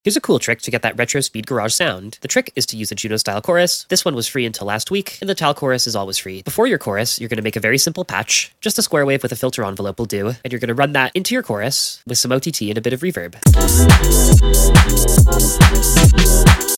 This FREE plugin gives you instant retro speed garage vibes! 🚨 Perfect for 140 BPM, 2-step, and jungle sounds.